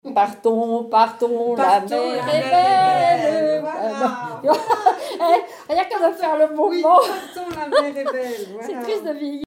témoignages et bribes de chansons
Pièce musicale inédite